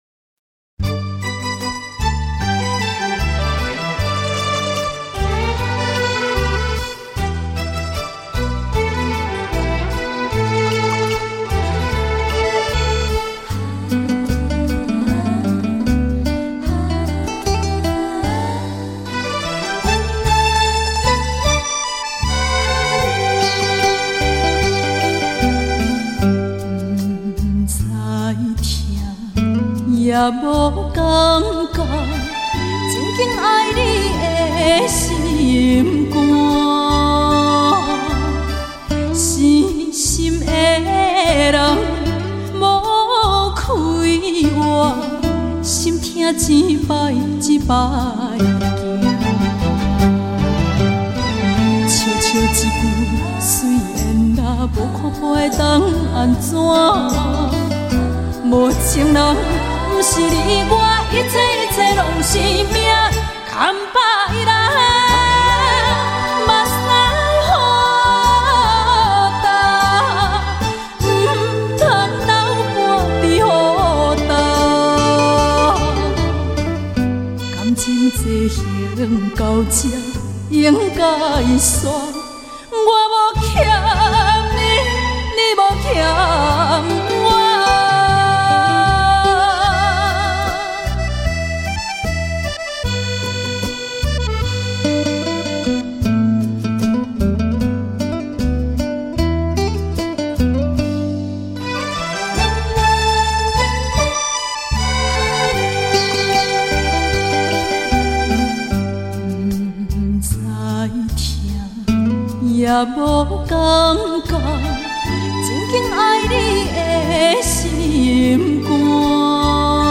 最深缘的演歌唱腔